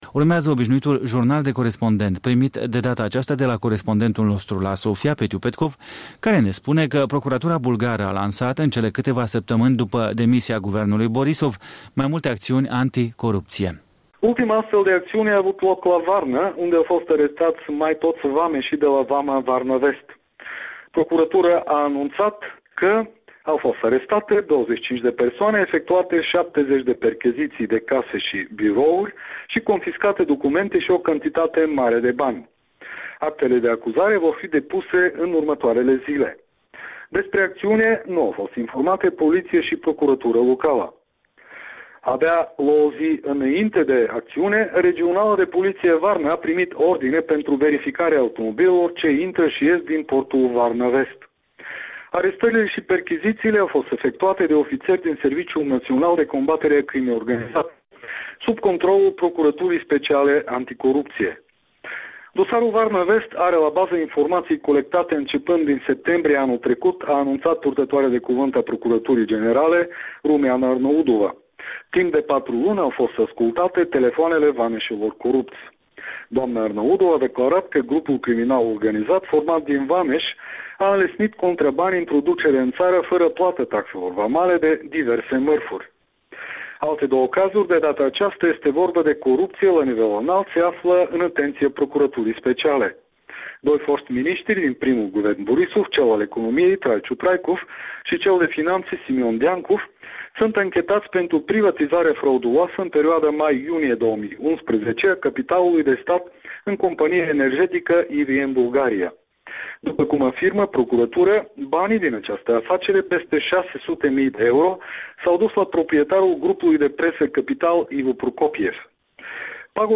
Jurnal de Corespondent